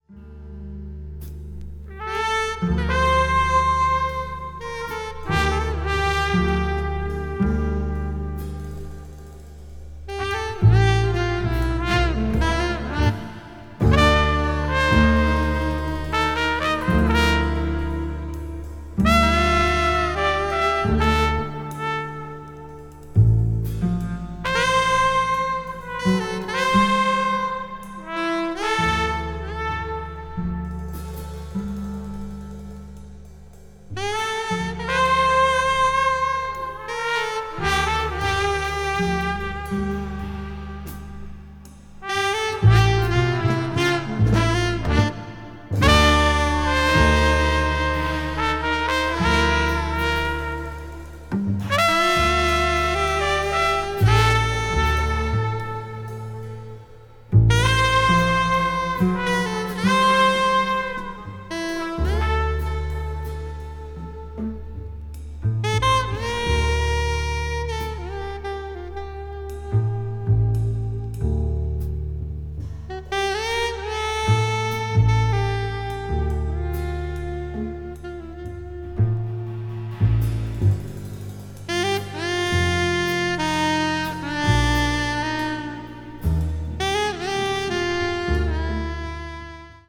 非常にスピリチュアルなサウンド
avant-jazz   contemporary jazz   spritual jazz